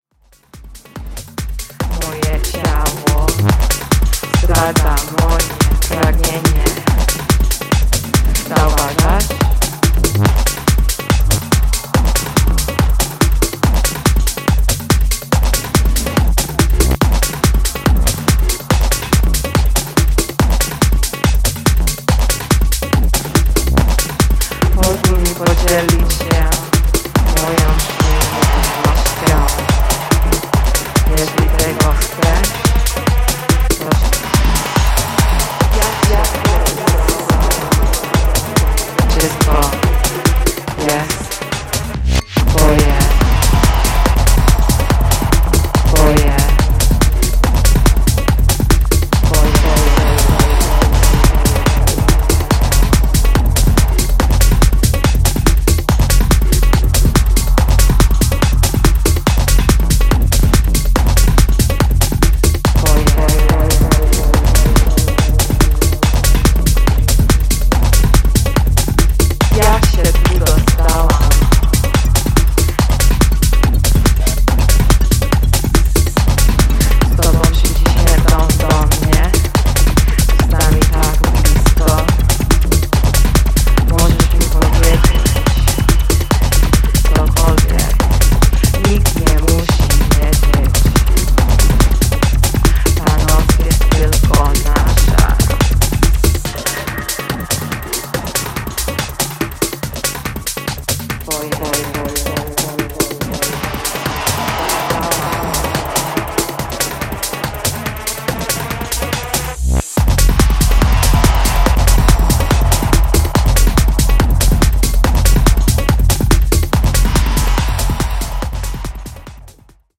provocative Polish vocals
Unhinged, raw, and chaotic, burning the whole barn down.
Genre Techno